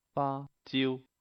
Cantonese (Jyutping) faa1 ziu1